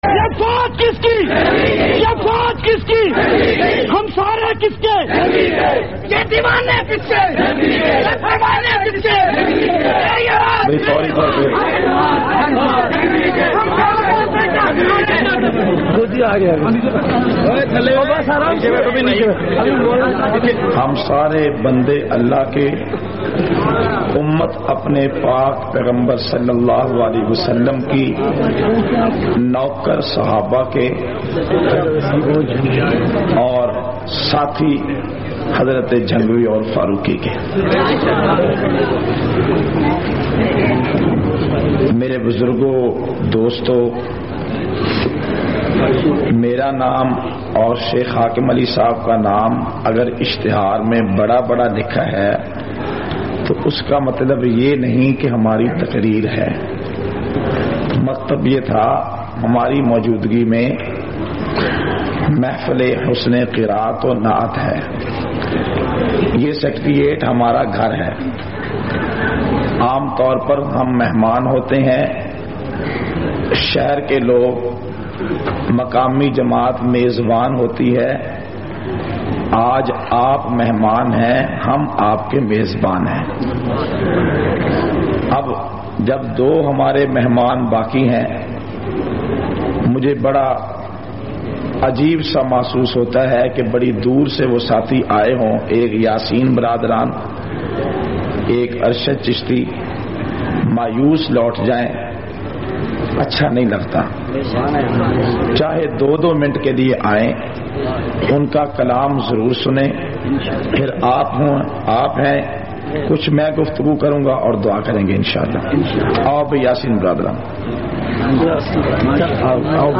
788- Mehfil e Hamd o Naat-Jiya Mosa,Lahore.mp3